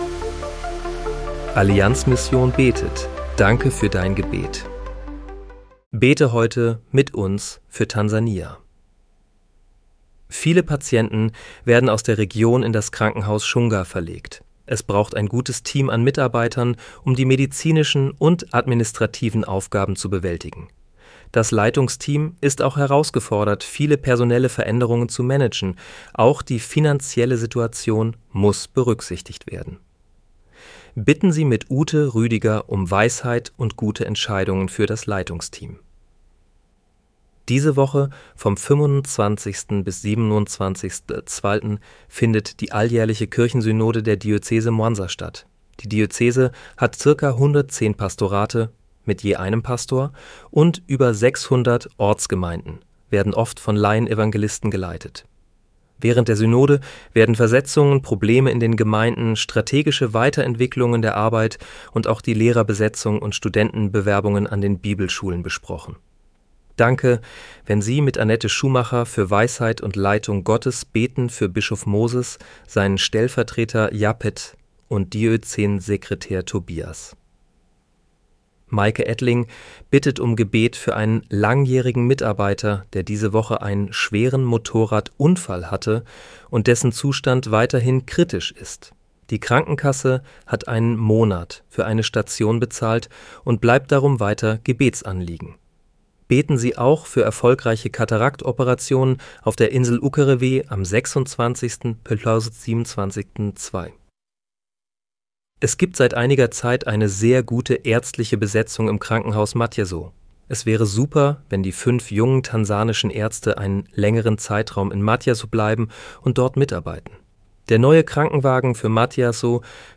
Bete am 25. Februar 2026 mit uns für Tansania. (KI-generiert mit